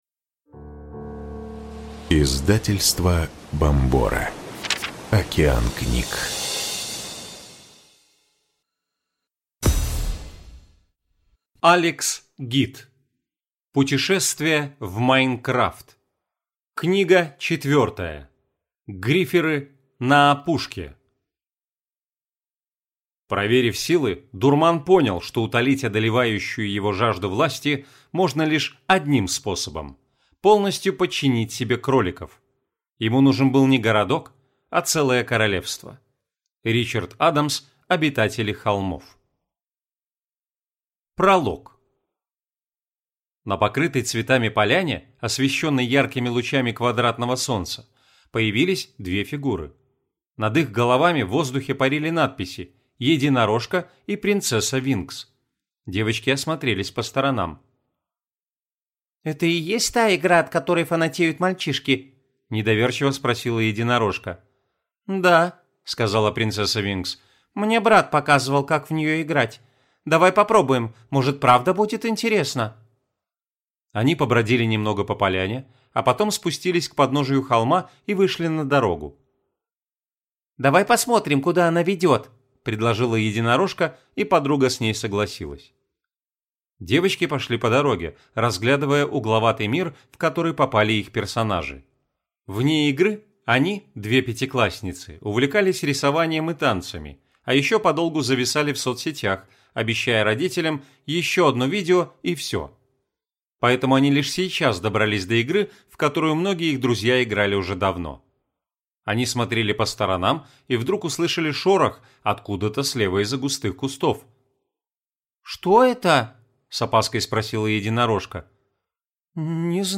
Aудиокнига Гриферы на опушке